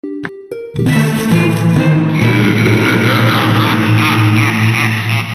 PlantsVsZombies Evil Laugh Sound Effects Free Download
PlantsVsZombies Evil laugh sound effects free download